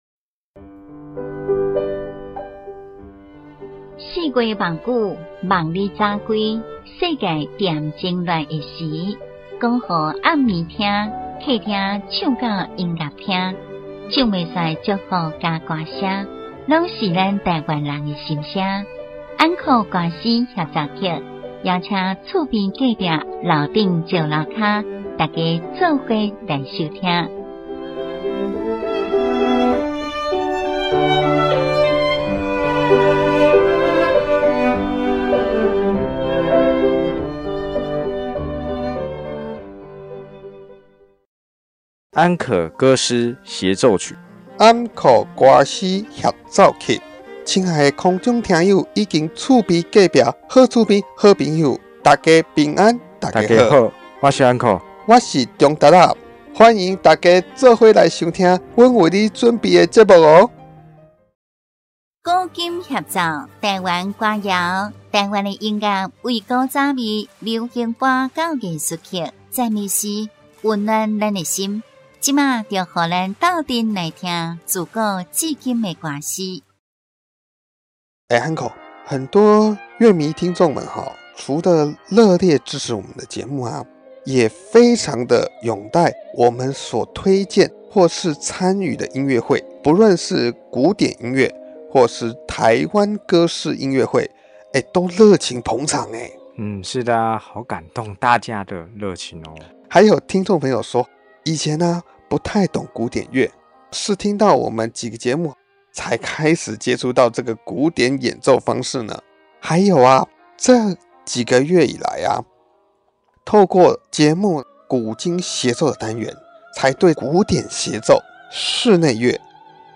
今日藉由回應樂迷們最常來訊提問有關鼓掌時機？持續分享熱誠提供名曲的名家名團及實力派音樂家、合唱團、專輯等演出片段